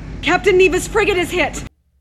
Dark Empire audio drama